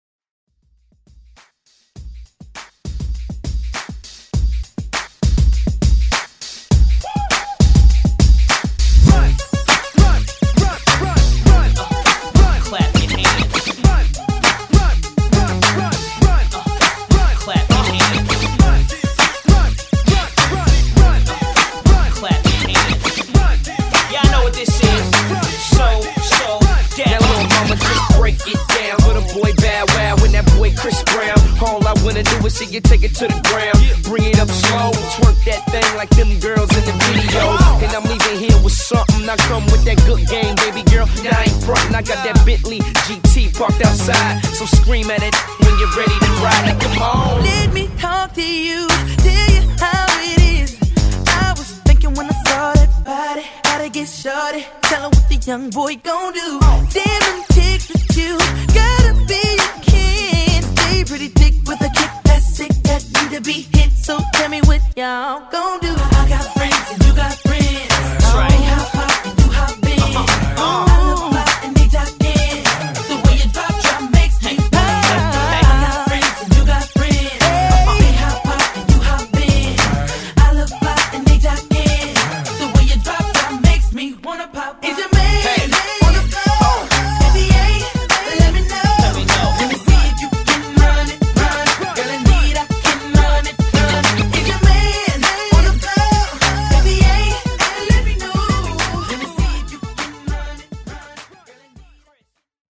BPM: 101 Time